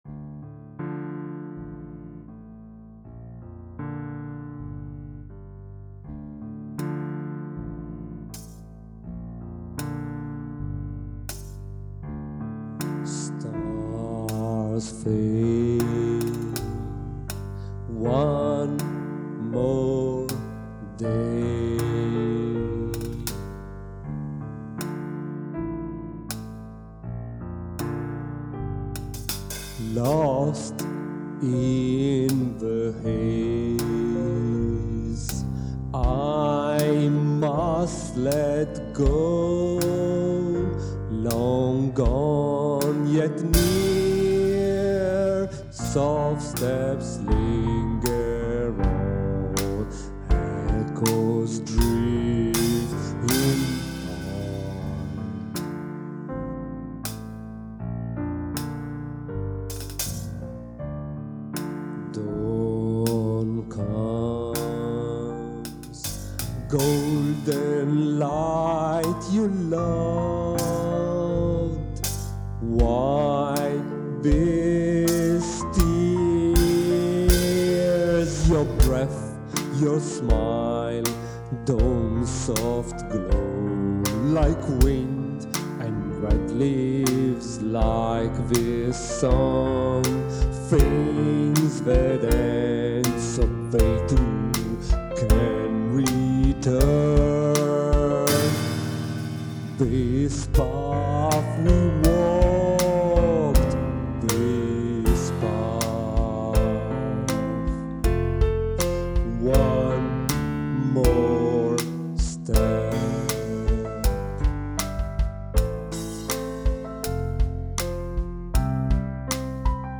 • Elegy (with voice)